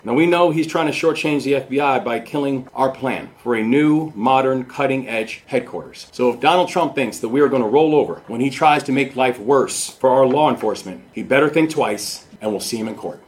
The lawsuit challenges the decision to abandon the decision to locate in Greenbelt and instead move to a building in the District of Columbia. Governor Wes Moore says the President is working against law enforcement…